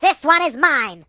project_files/Data/Sounds/voices/British/Thisoneismine.ogg
Thisoneismine.ogg